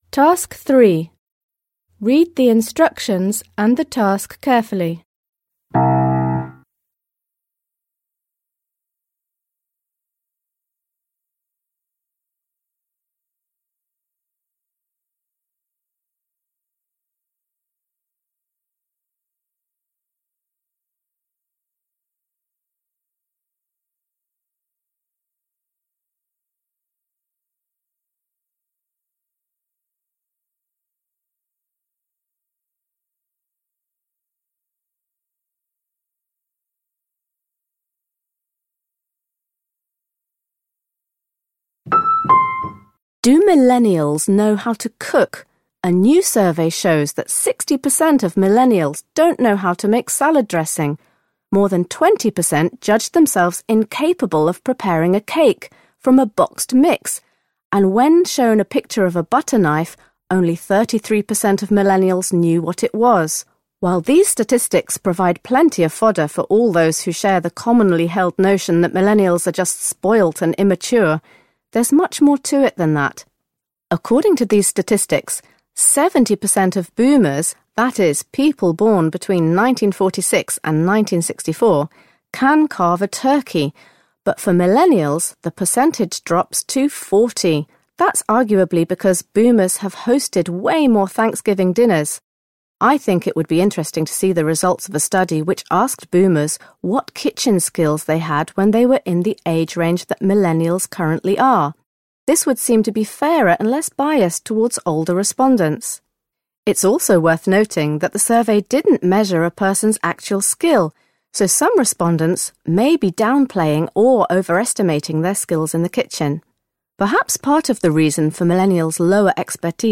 You are going to hear a radio programme on millennials’ ability to cook.